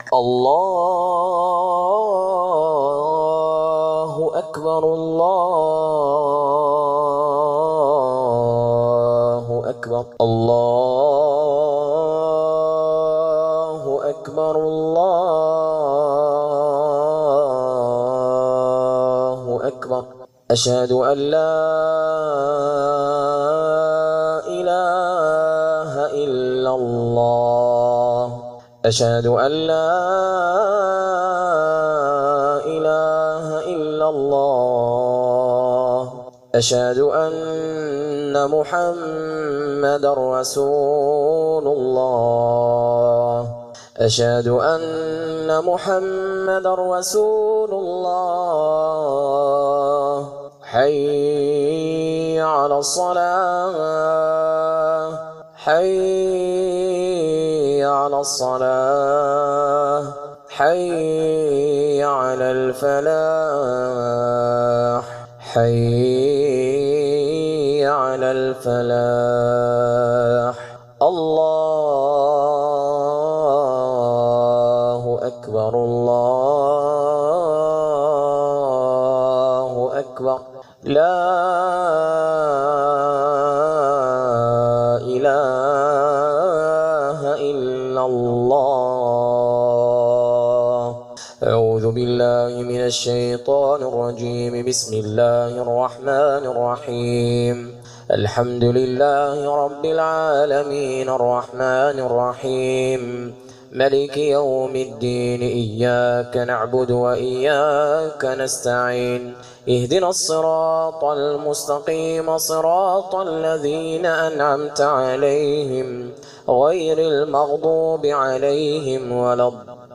বন্ধ্যাত্বের যাদু নষ্টের রুকইয়াহ